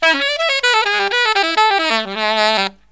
63SAXMD 02-R.wav